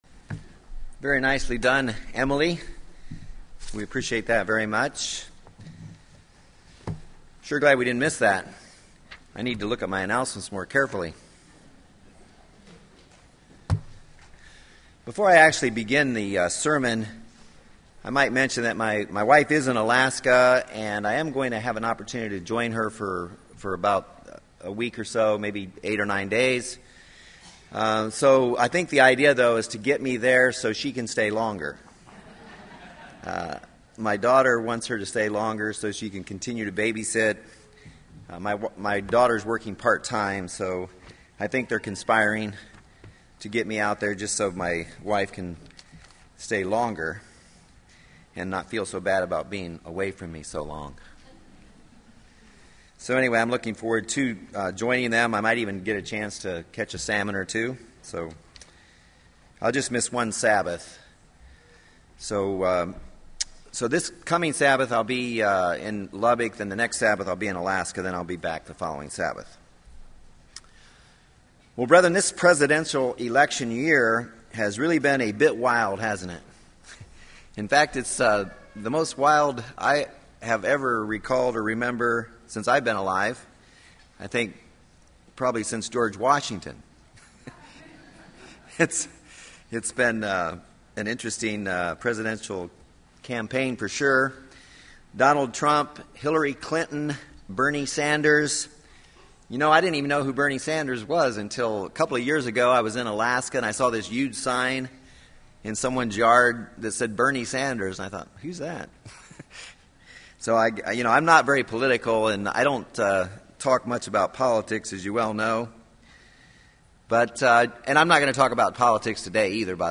This sermon will discuss four biblical principles leading to a more spiritually balanced and godly life.